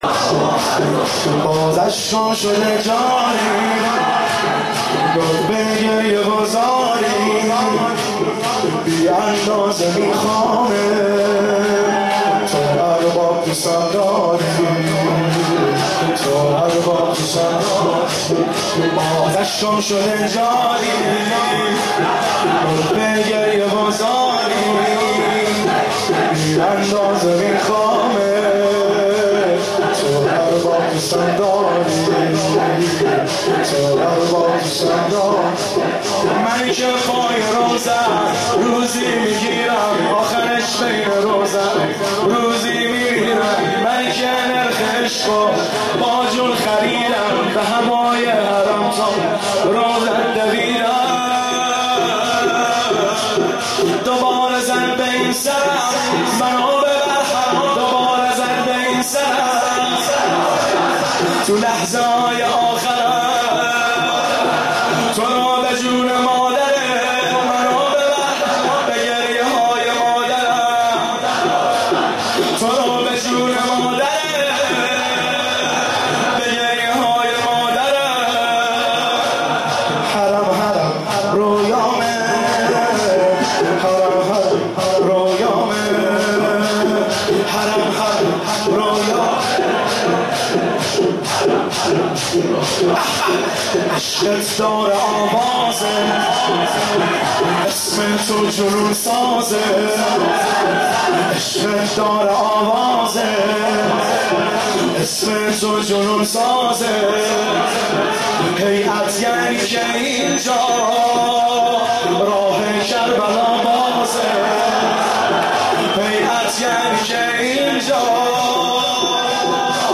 که در حیدریه کرمانشاه اجرا شده است
تا باشه خدا من نوکرتم،من گریه کن ِ روضه های مادرتم ( شور )
یه دلبرُ هزار و یک خوبی،یه نوکری که خیلی دلتنگِ ( واحد )